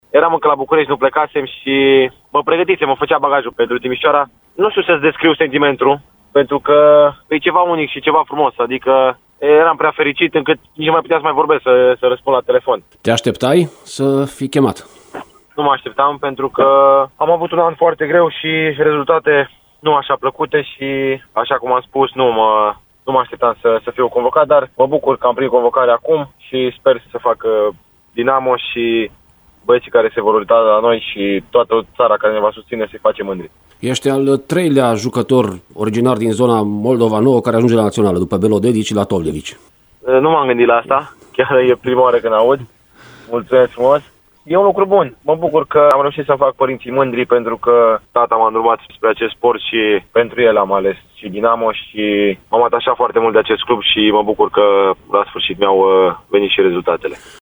Deian a declarat într-un interviu pentru Radio Timișoara că din pricina sezonului greu parcurs de actuala sa echipă – Dinamo București – nu se gândea că va fi chemat sub tricolor: